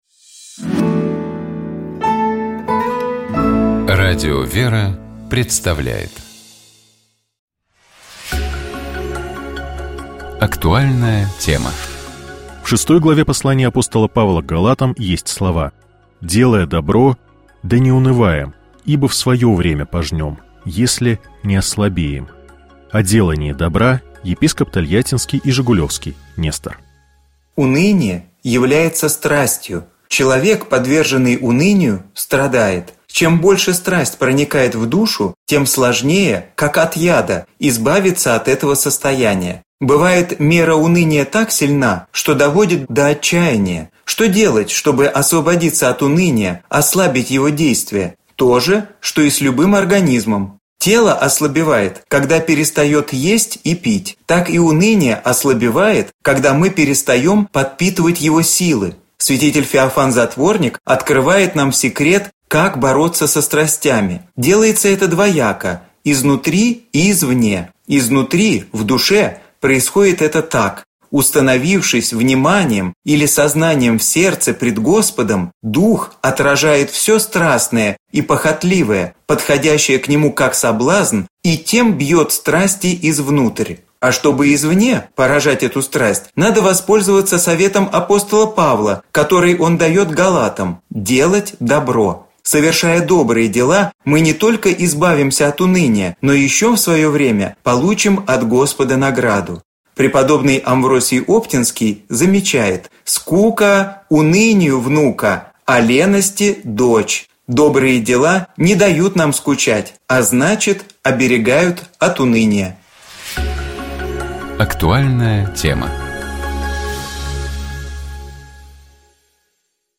О делании добра — епископ Тольяттинский и Жигулёвский Нестор.